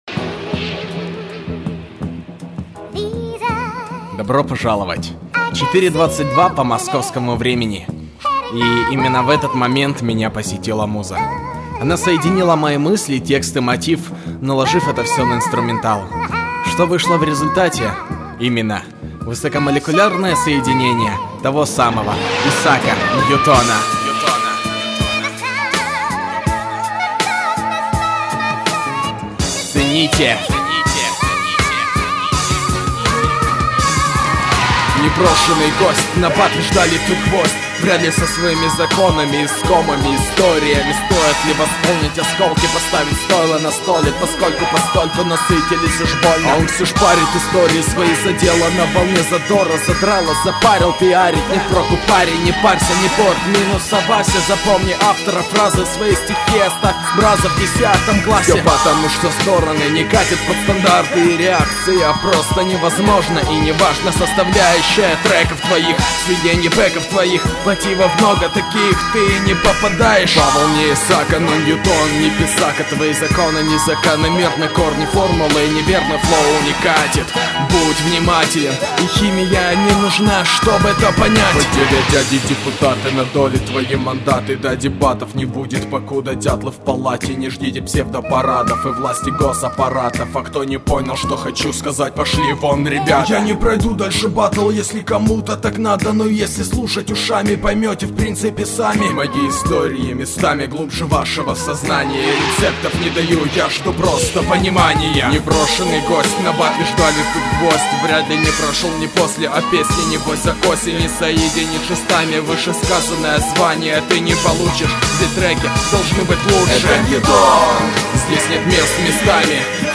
• Жанр: Бардрок